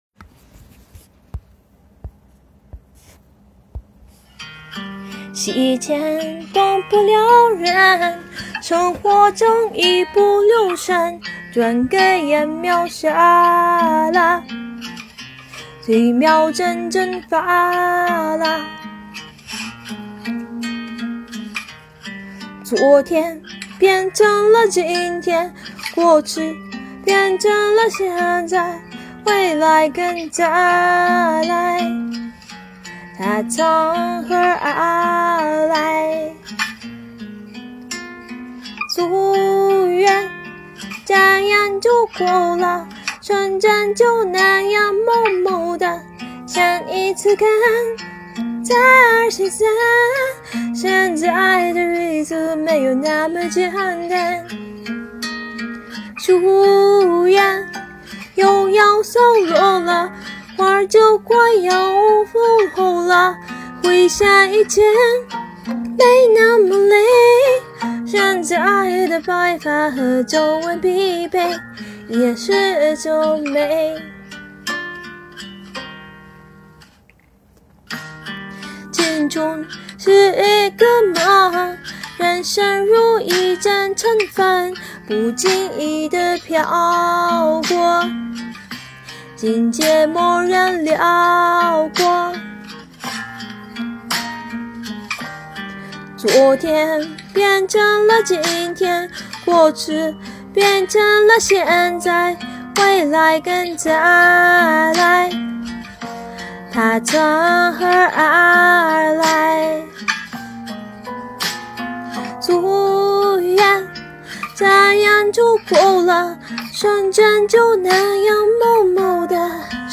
歌唱コンテスト結果発表
・旋律很美，歌声悠扬，咬字更清晰些会更好哦~
・应该是自弹自唱吧， 很厉害。
・吐字很清楚，还有吉他的伴奏非常好听